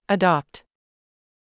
🔤 発音と読み方：/əˈdɑːpt/（米）｜/əˈdɒpt/（英）
• əˈdɑːpt（米）：「アドーpt」（「ド」にアクセント）